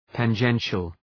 Προφορά
{tæn’dʒenʃəl}
tangential.mp3